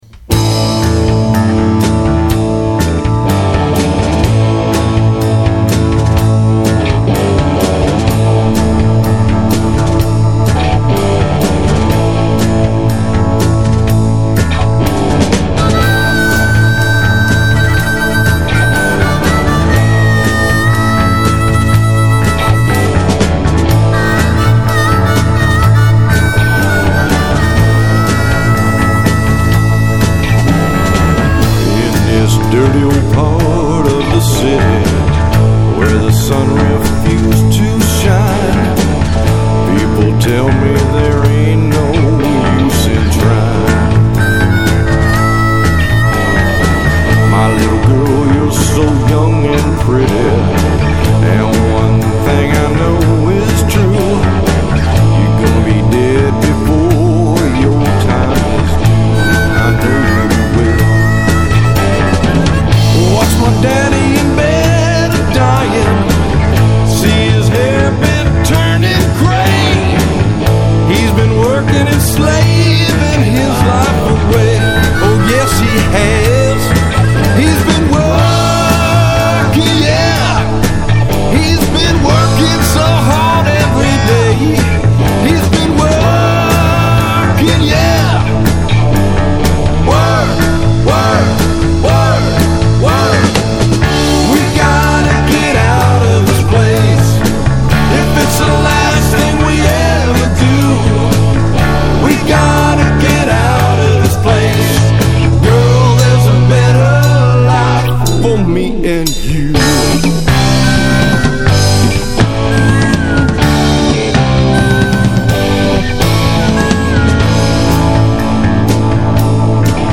and the rest of the band doing background vocals.
harmonica
B3 Hammond organ